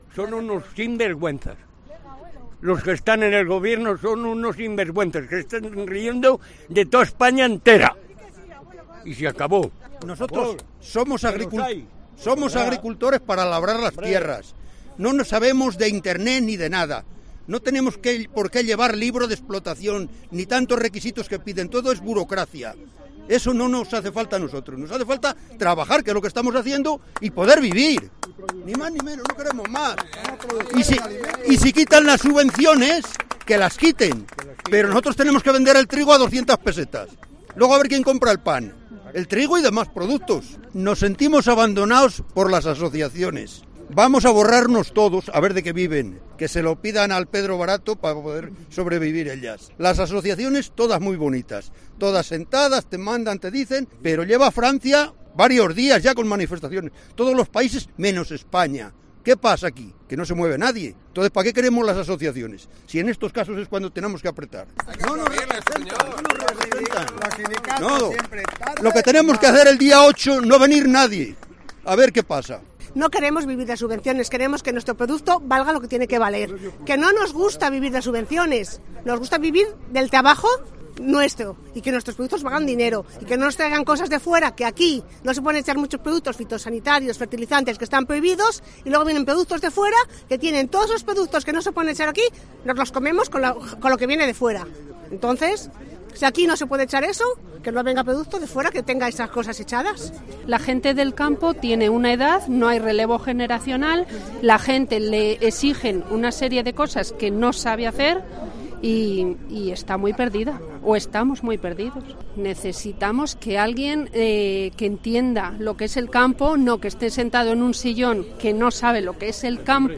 Los manifestantes explican a COPE sus reivindicaciones
Los micrófonos de COPE han recogido hoy los testimonios de un grupo de productores concentrados en la Plaza de España de la capital salmantina.